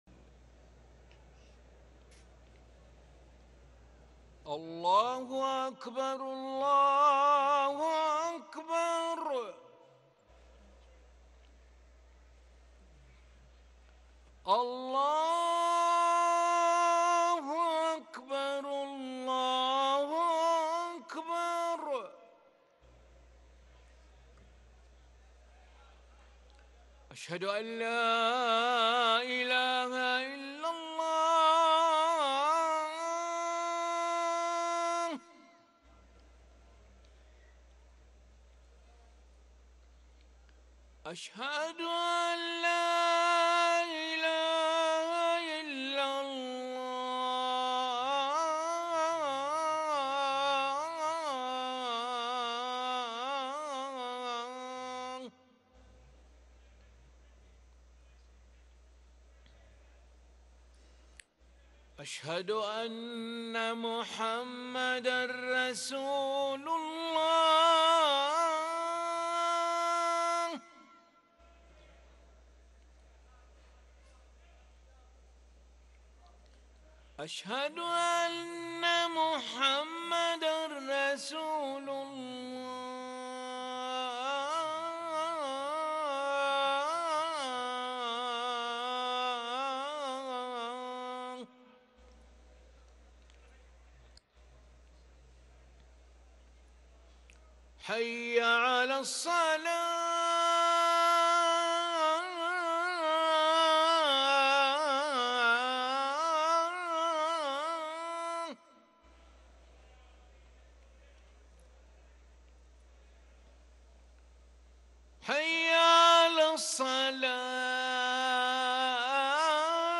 أذان العشاء للمؤذن علي ملا الأحد 27 ربيع الأول 1444هـ > ١٤٤٤ 🕋 > ركن الأذان 🕋 > المزيد - تلاوات الحرمين